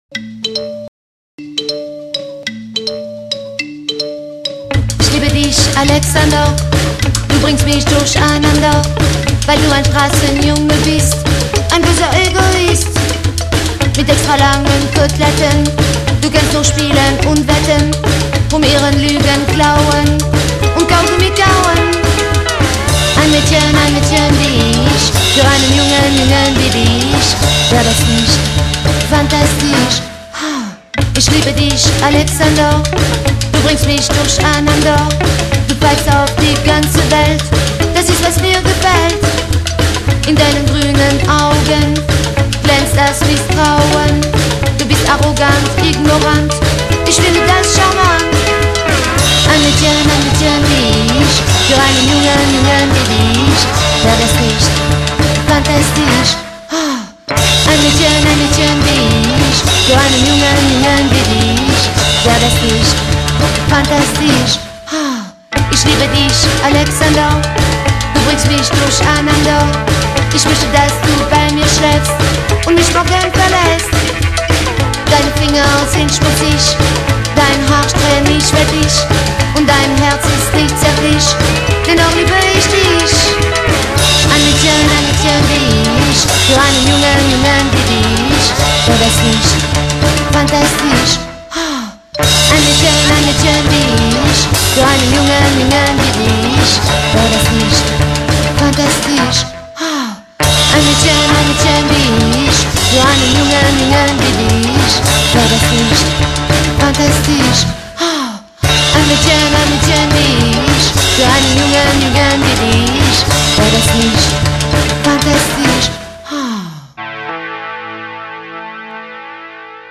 But most of all, it's just good old-fashioned Germpop.